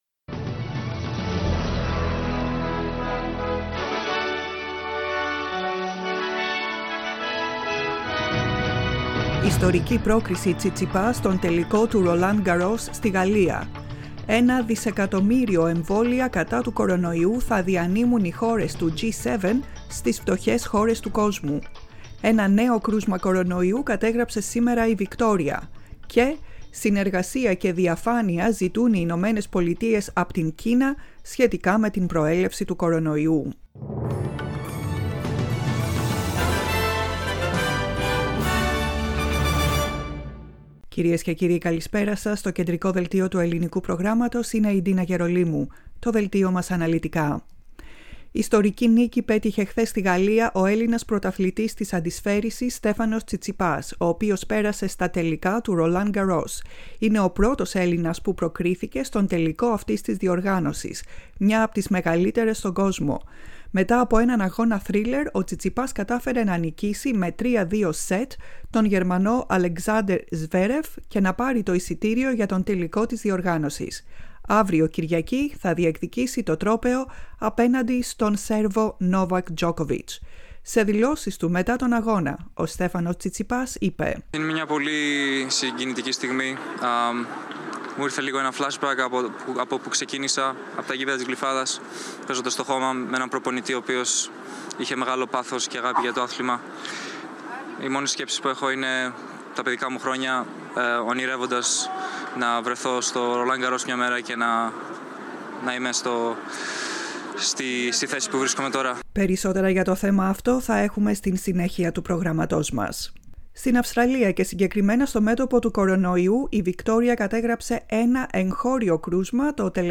Το κεντρικό δελτίο ειδήσεων του Ελληνικού Προγράμματος.